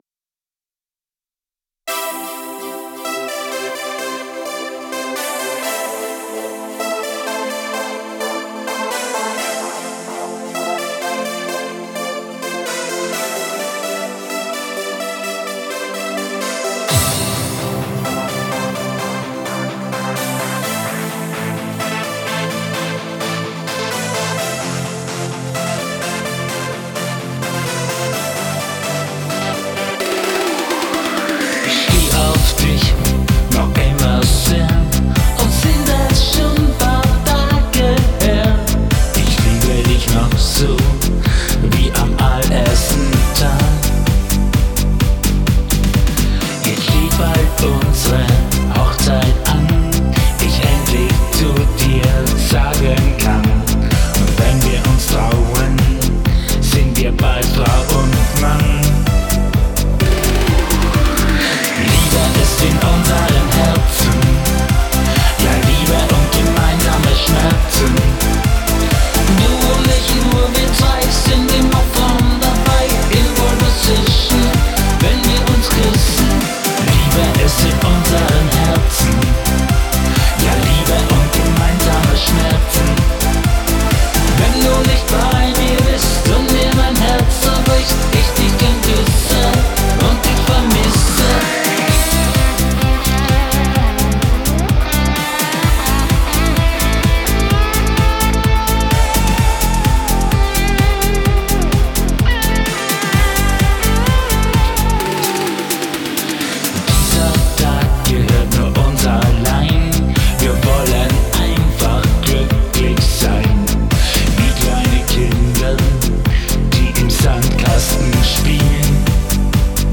[Pop-Schlager] Liebe ist in unseren Herzen